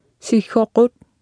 Speech synthesis Martha to computer or mobile phone
Speech Synthesis Martha